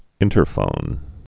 (ĭntər-fōn)